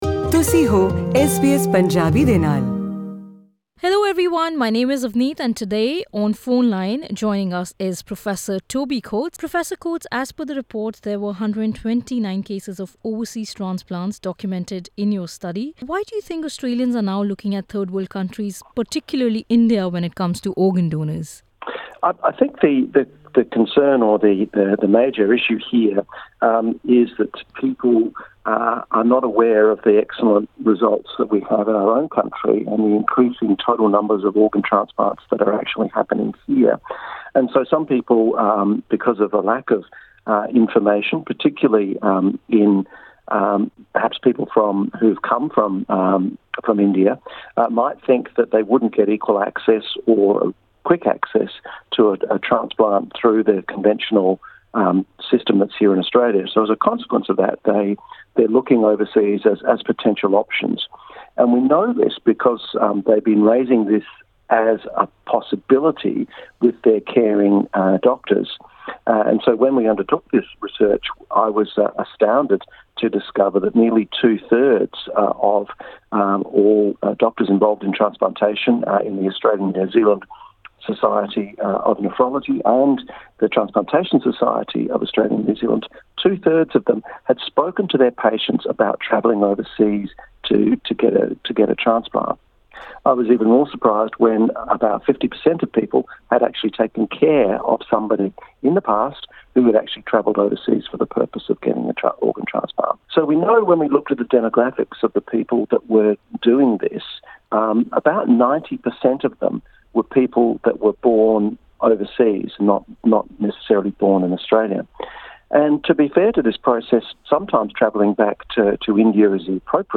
ਉਪਰ ਦਿੱਤੇ ਲਿੰਕ ਤੇ ਕਲਿੱਕ ਕਰਕੇ, ਪੂਰੀ ਗਲਬਾਤ ਸੁਣੋ।